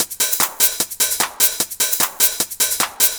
150_HH+shaker_1.wav